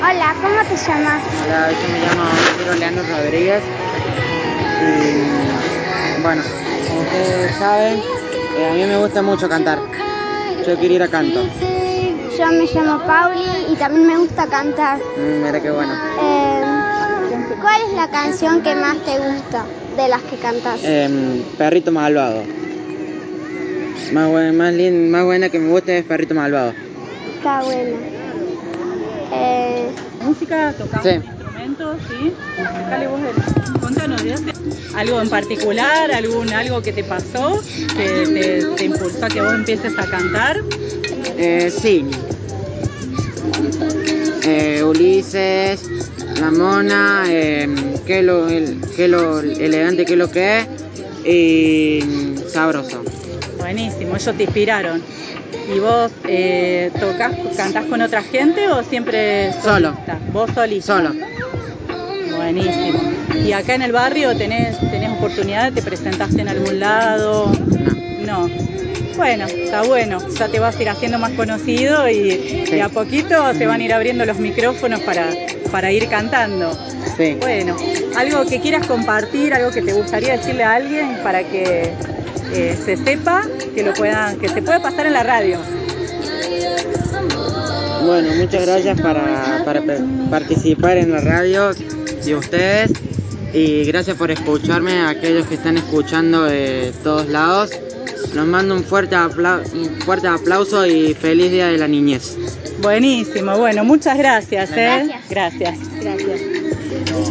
Radio del Monte estuvo presente en la fiesta que las vecinas y vecinos de Cumbre Azul organizaron para las niñas  y niños del barrio.
Entrevista